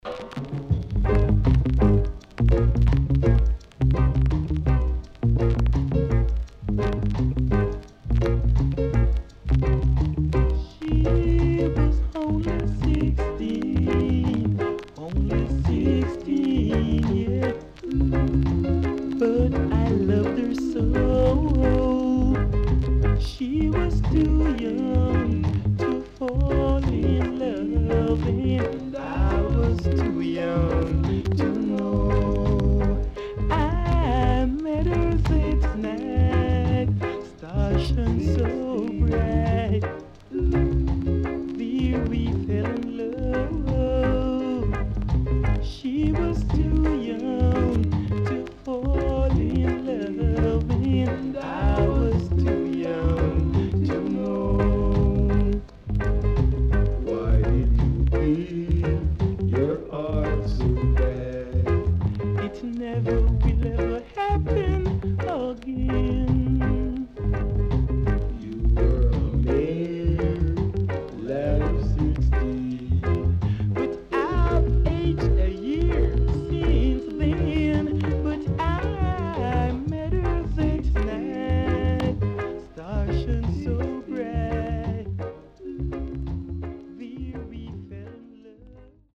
ROCKSTEADY
CONDITION SIDE B:VG(OK)
SIDE B:所々チリノイズがあり、少しプチノイズ入ります。